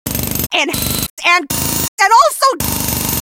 jackie_die_vo_02.ogg